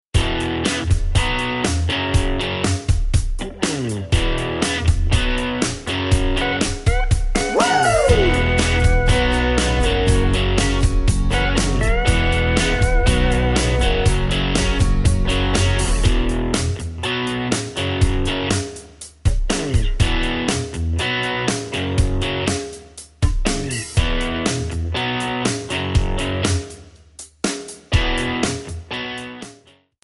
Backing track Karaoke
Country, Duets, 2000s